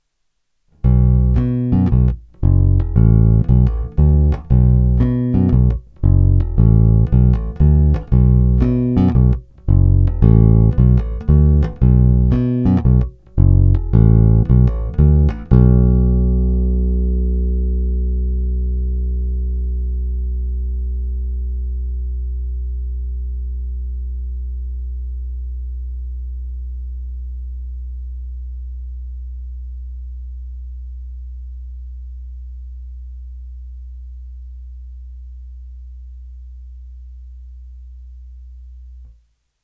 Masívní basy, vrčivé středy, kousavé výšky.
Není-li uvedeno jinak, následující nahrávky jsou nahrány rovnou do zvukovky a dále jen normalizovány. Použité struny jsou niklové roundwound struny D'Addario v tloušťkách .045"-.065"-.085"-.105" v novém stavu.
Hra mezi krkem a snímačem